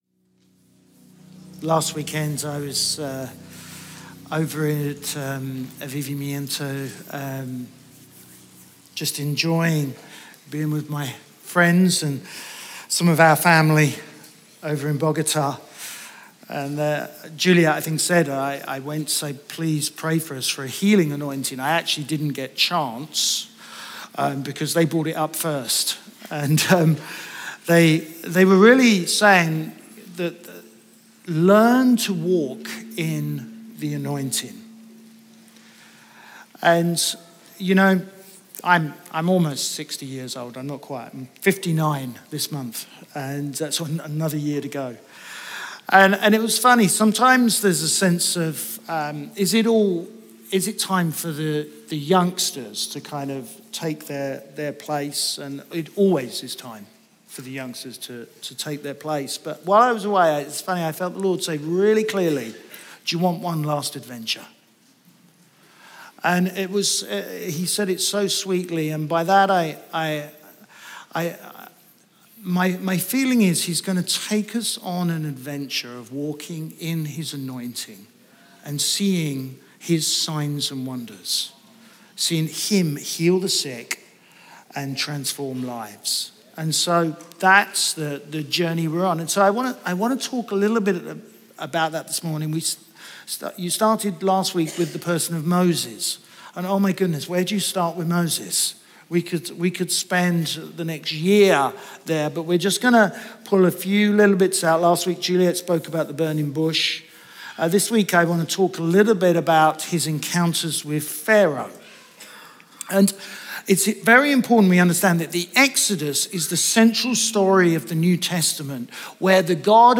Chroma Church - Sunday Sermon Moses – signs and wonders Mar 30 2023 | 00:29:02 1x 00:00 / 00:29:02 Subscribe Share RSS Feed Share Link Embed